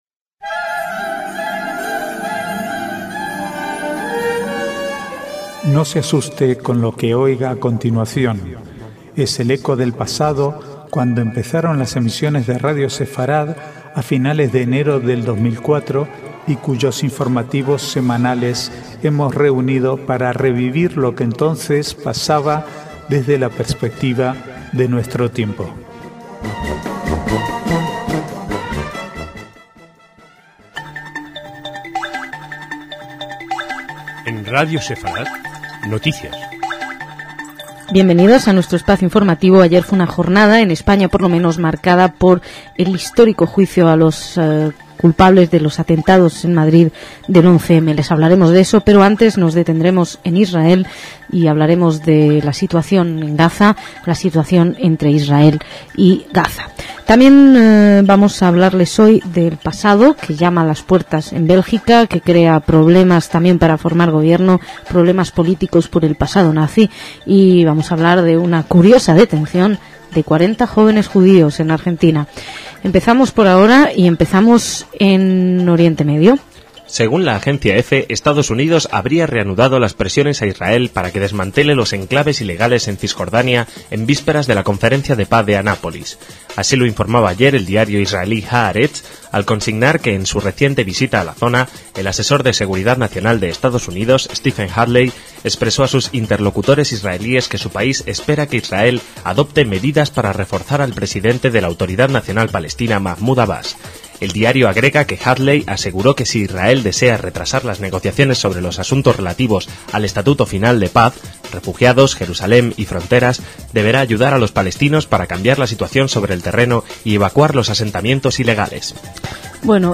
Archivo de noticias del 1 al 6/11/2007
Es el eco del pasado, cuando empezaron las emisiones de Radio Sefarad a finales de enero de 2004 y cuyos informativos semanales hemos reunido para revivir lo que entonces pasaba desde la perspectiva de nuestro tiempo.